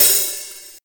soft-hitnormal1.ogg